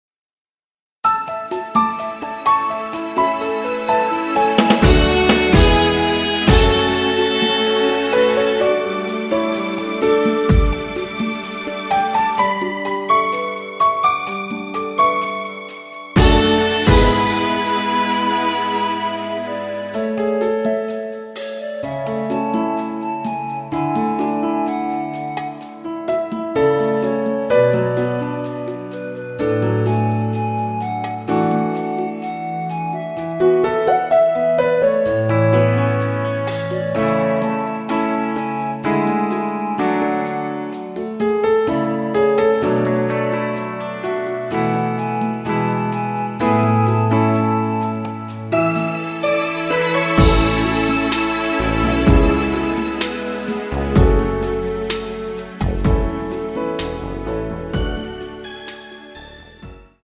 원키 멜로디 포함된 MR 입니다.
앞부분30초, 뒷부분30초씩 편집해서 올려 드리고 있습니다.
중간에 음이 끈어지고 다시 나오는 이유는
(멜로디 MR)은 가이드 멜로디가 포함된 MR 입니다.